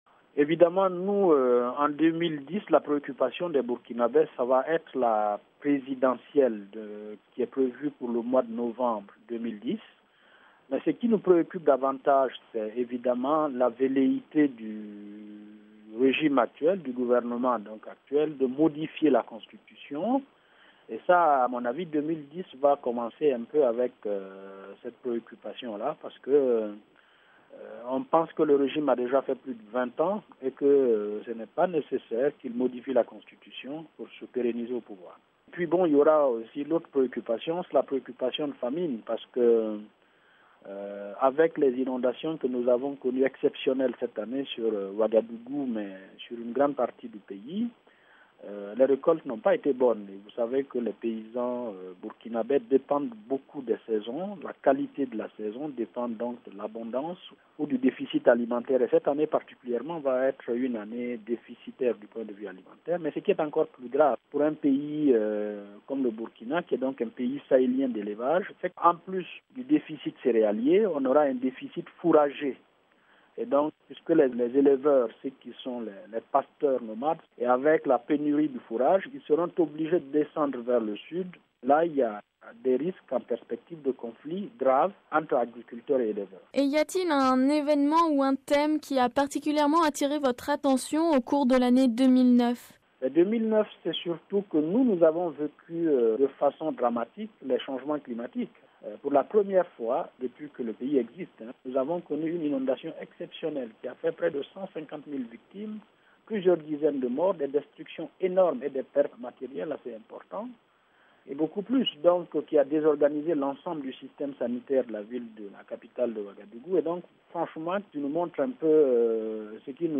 Nous vous proposons d’écouter une série d’entretiens de fin d’année avec quelques responsables de la presse écrite francophone. Qu’ont-ils retenu de l’année 2009 ?